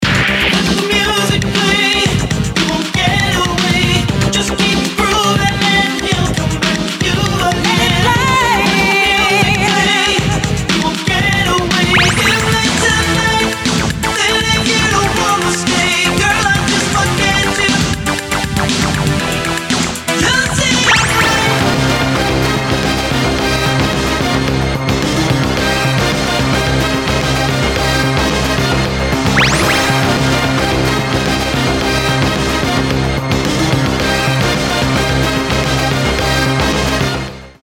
• Качество: 256, Stereo
поп
disco
ретро
Synth-pop mix